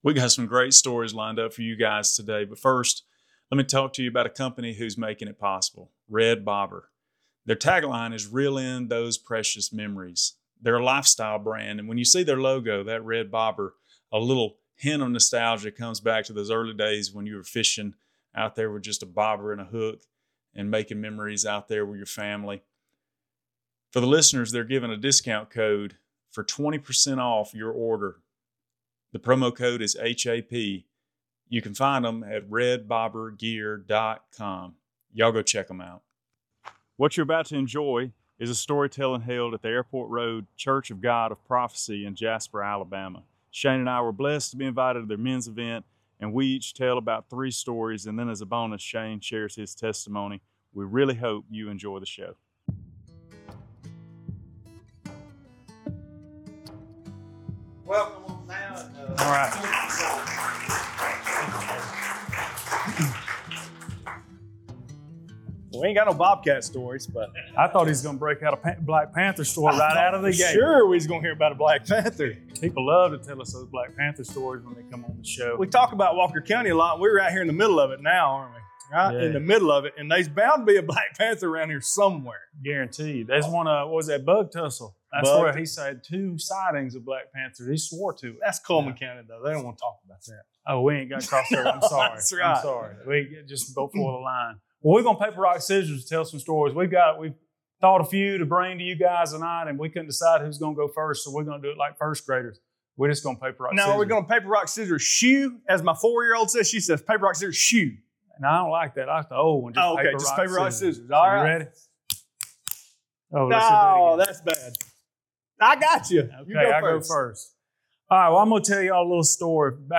We hope you enjoy watching this men's event at Airport Road Church of God of Prophecy in Jasper, Alabama.